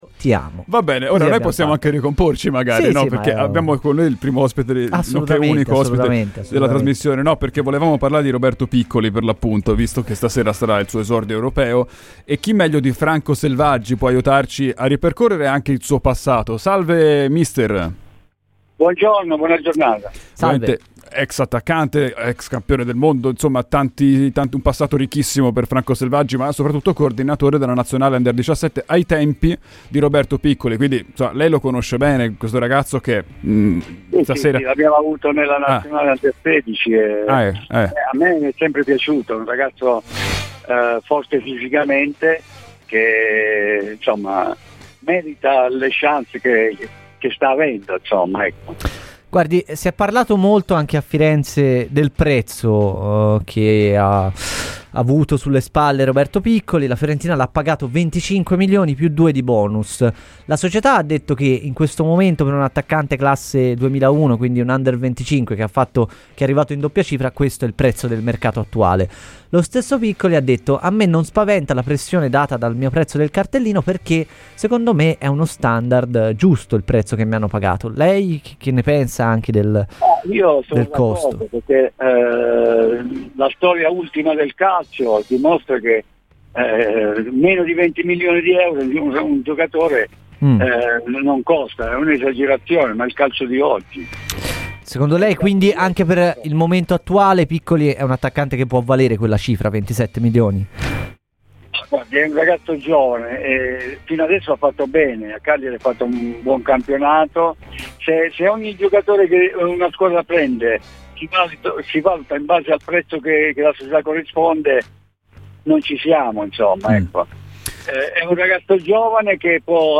L'ex attaccante del Cagliari Franco Selvaggi è intervenuto a Radio FirenzeViola durante "I tempi Supplementari" per parlare di Roberto Piccoli da lui avuto nelle nazionali giovanili e che stasera debutta in Europa: "A me piace molto, è forte fisicamente, merita le chance che sta avendo".